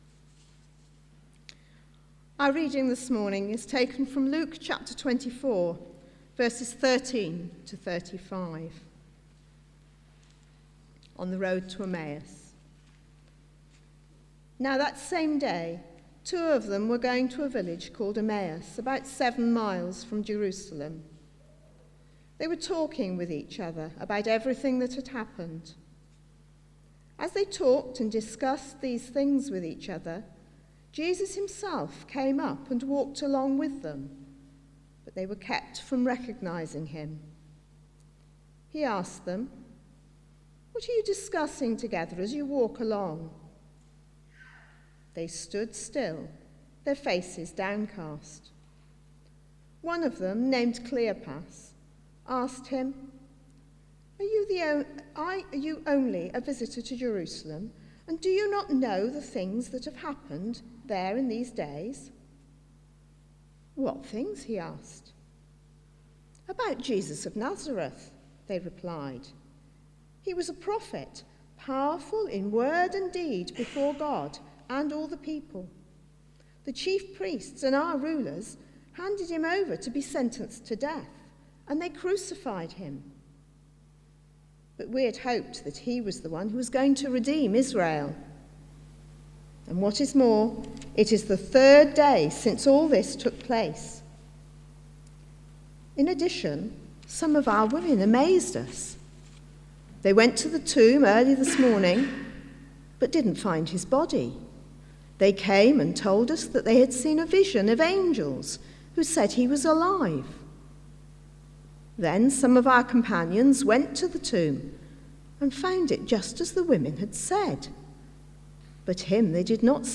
Service Type: Sunday 11:00am